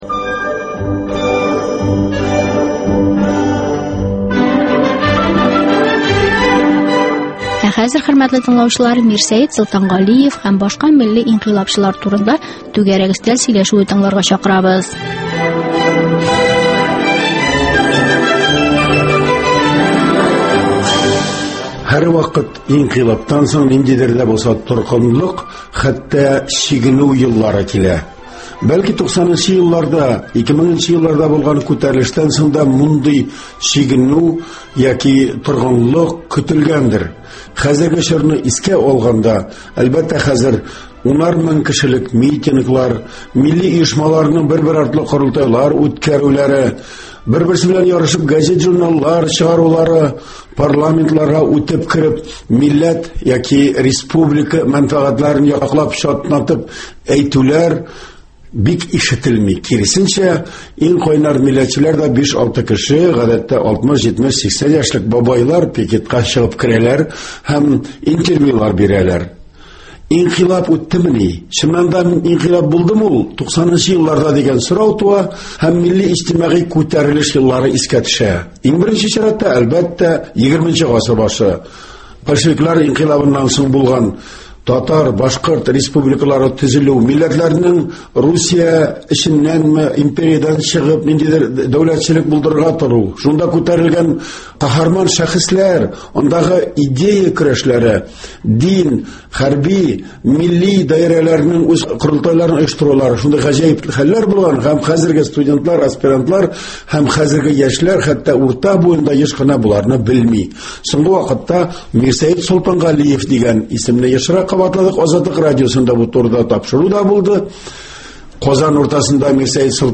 Түгәрәк өстәл сөйләшүе: Мирсәет Солтангалиев һәм татар инкыйлабчылары